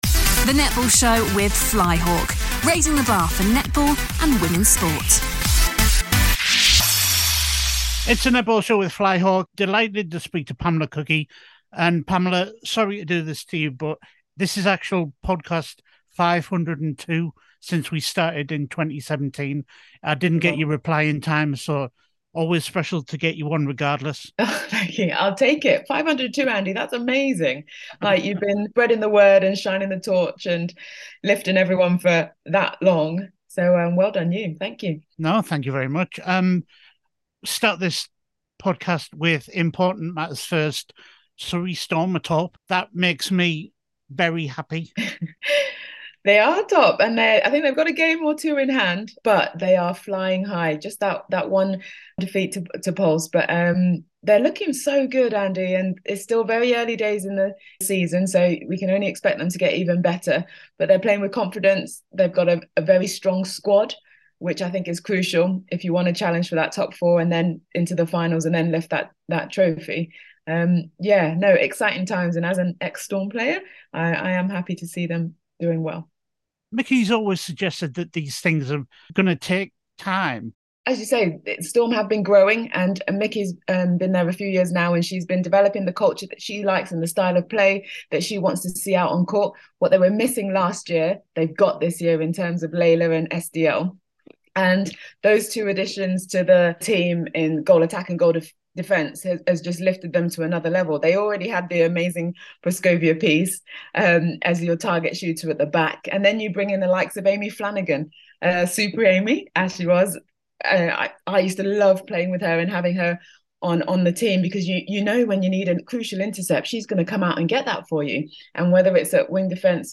Pamela joins us for a chat on how the Superleague season is shaping up so far this season - Great chat on all the teams from the sides!